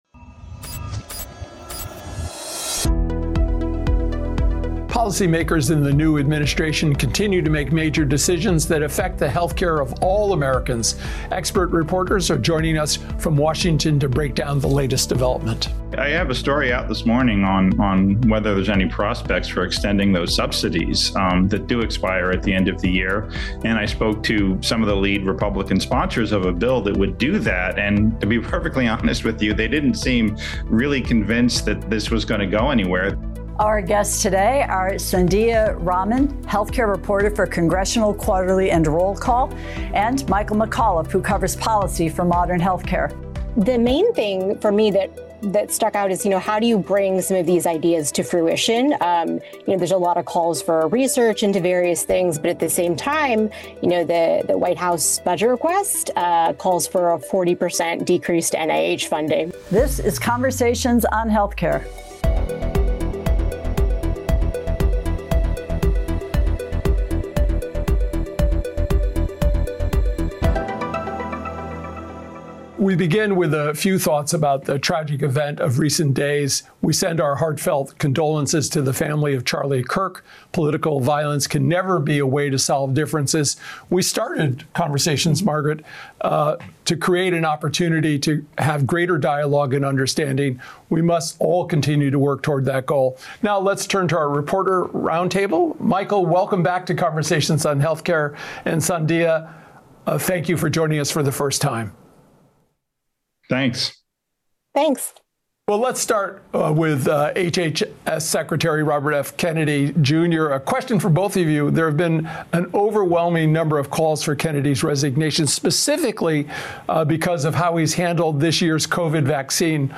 join our Reporters’ Roundtable.